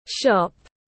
Cửa hàng tiếng anh gọi là shop, phiên âm tiếng anh đọc là /ʃɒp/.